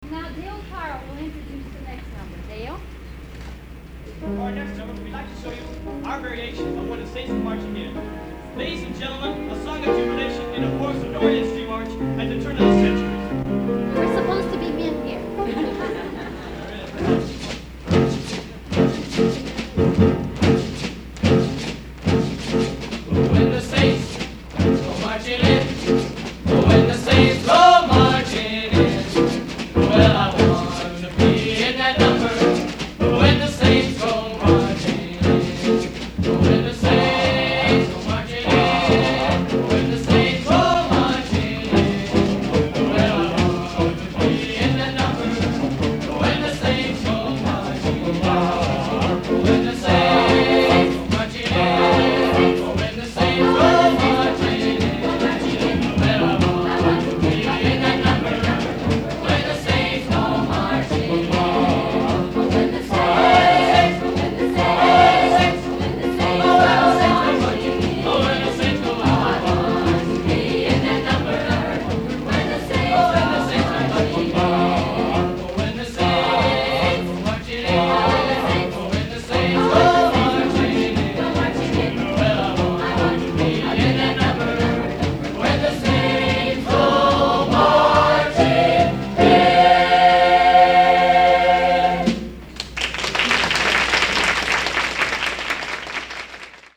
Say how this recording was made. Recorded at the Elks Club for St Joseph County Nurses Assoc.